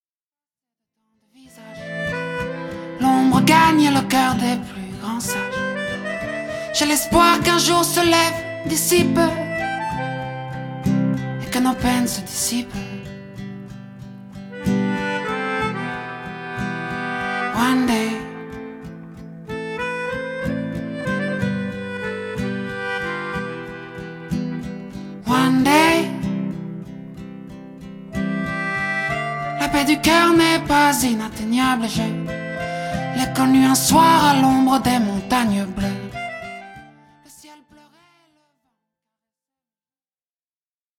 reggae
Enregistré dans un grand studio de Bruxelles